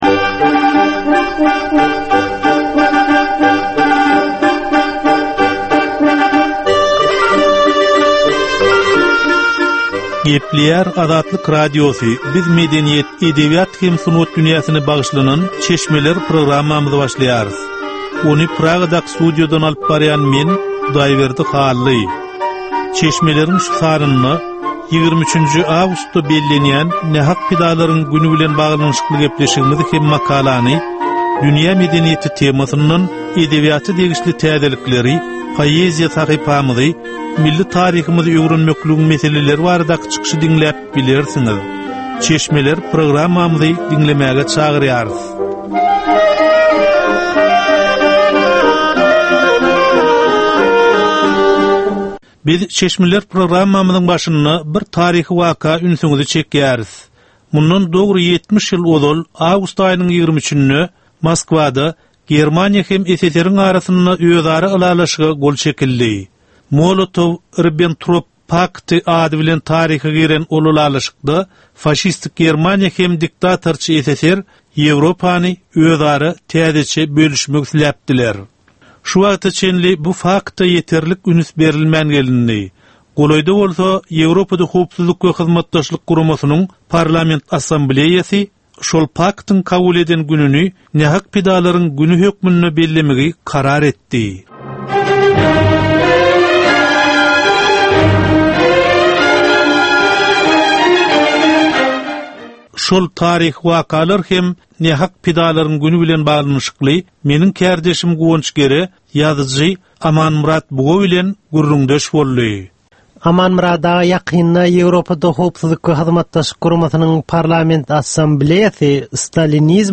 Edebi, medeni we taryhy temalardan 25 minutlyk ýörite geplesik.